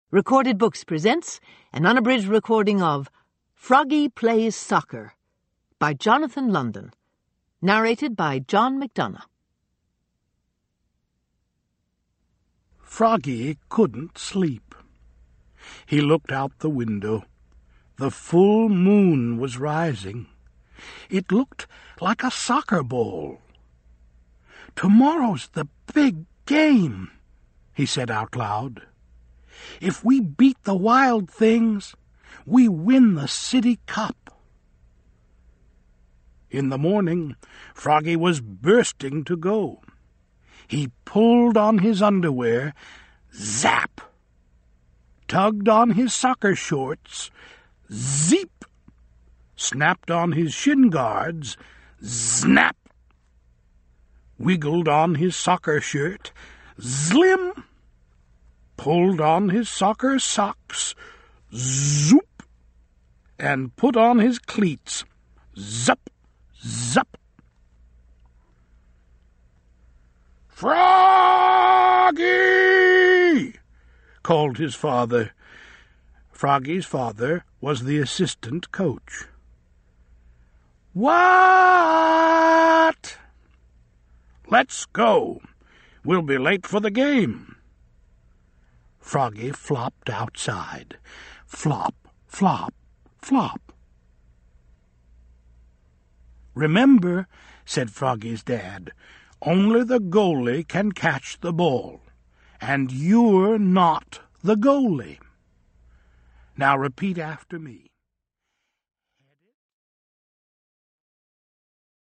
(OverDrive MP3 Audiobook, OverDrive Listen)
Narrator:
Unabridged